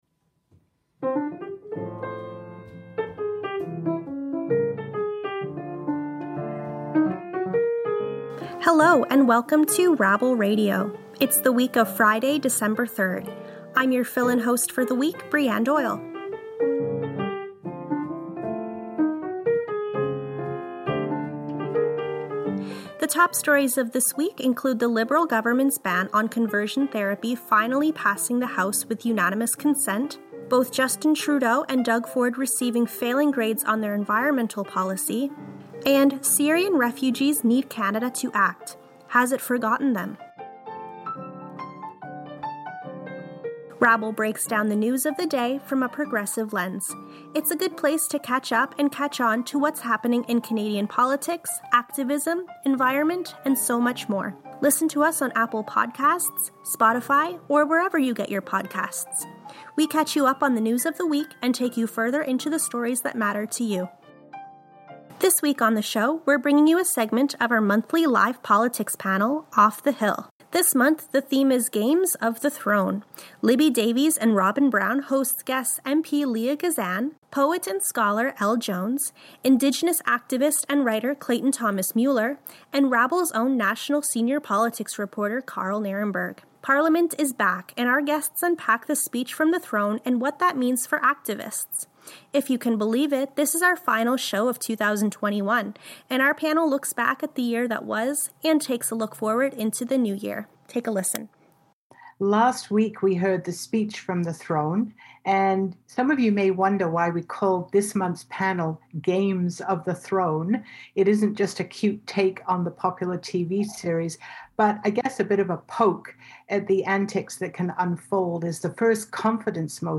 We share a clip from our most recent monthly political panel, Off the Hill